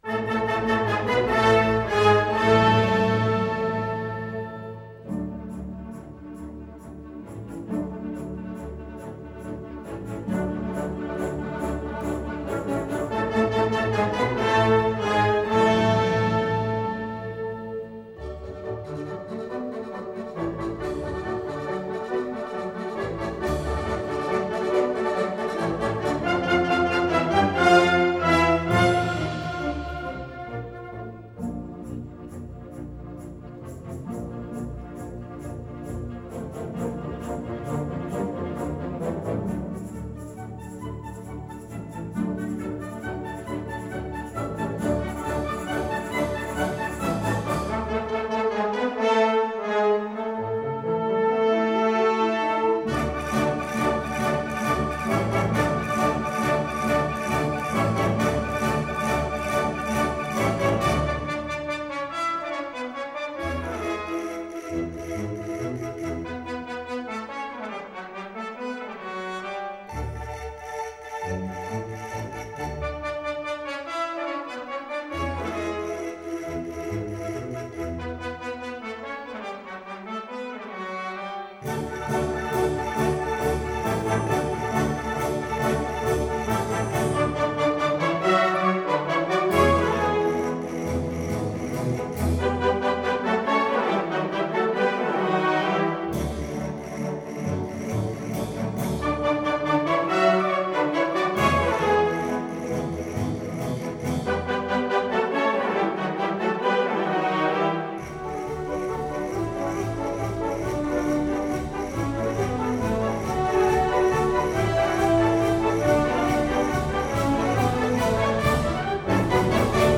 rosa del azafrán. fantasía. banda madrid. 1995.mp3